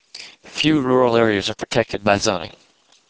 M: Male, F: Female